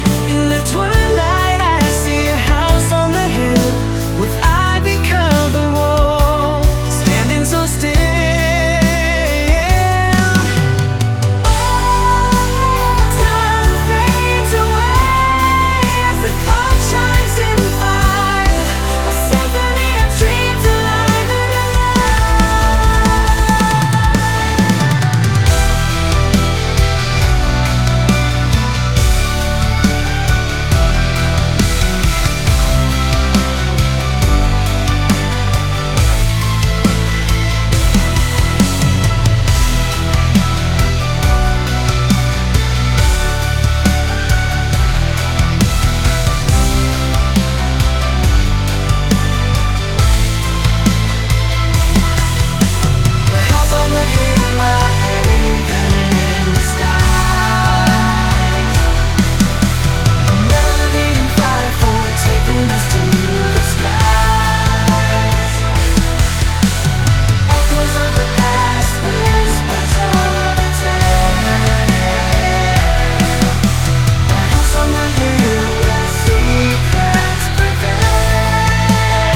An AI delta blues—and it’s really good